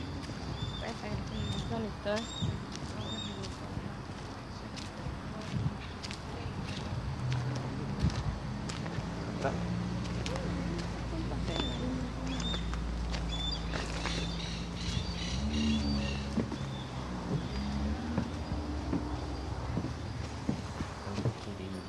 Harris´s Hawk (Parabuteo unicinctus)
Life Stage: Immature
Province / Department: Buenos Aires
Location or protected area: Ingeniero Maschwitz
Condition: Wild
Certainty: Observed, Recorded vocal